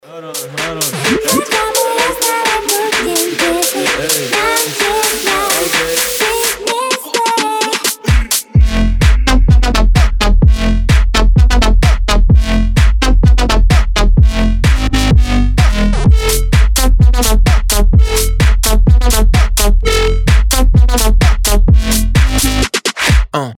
• Качество: 320, Stereo
мощные басы
Mashup
ремиксы